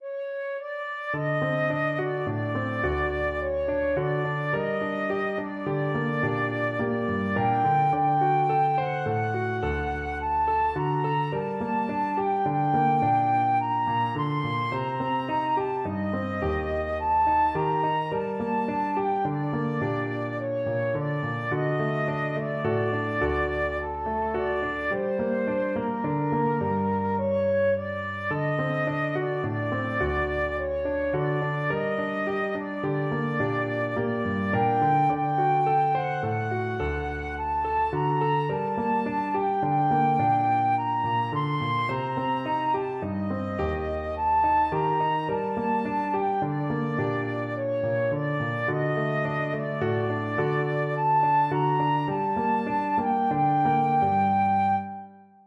3/4 (View more 3/4 Music)
Slow Waltz = c. 106
Arrangement for Flute and Piano
Gypsy music for flute